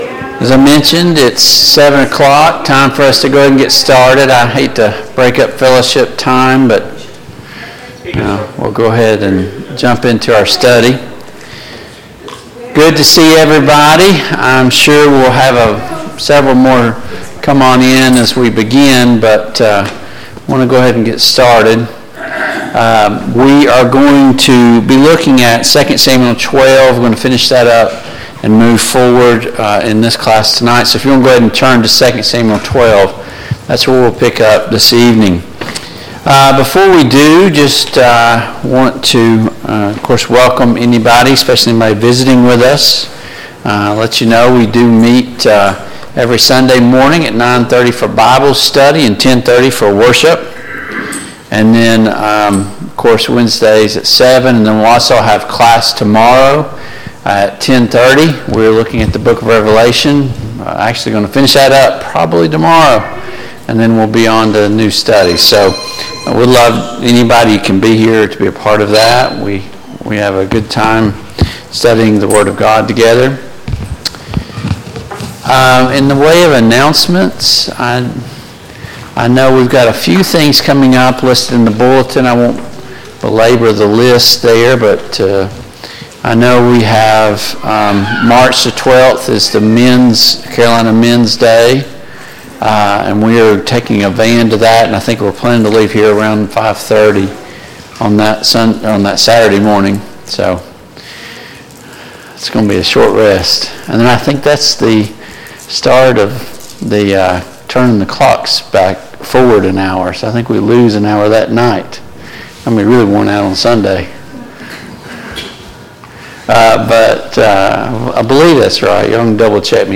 The Kings of Israel Passage: II Samuel 12, II Samuel 13, II Samuel 14 Service Type: Mid-Week Bible Study Download Files Notes « What does it mean to be truly powerful?